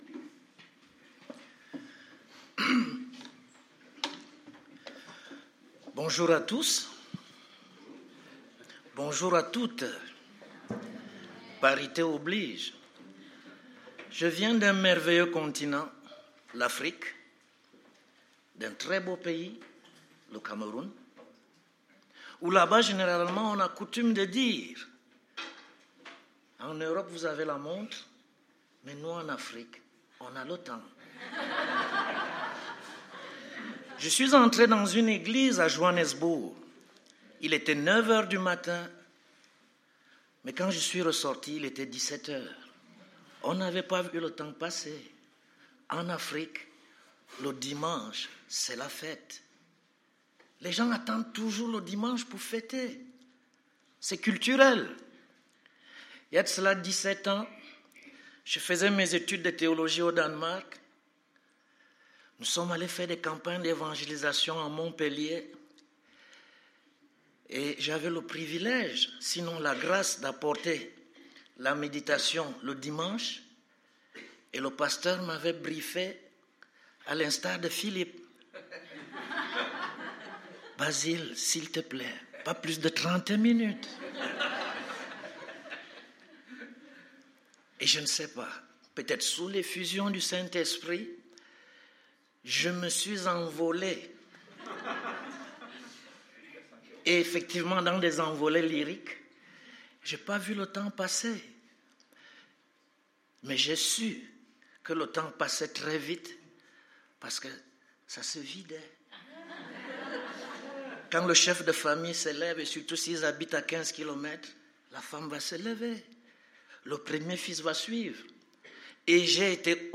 Culte du 12 novembre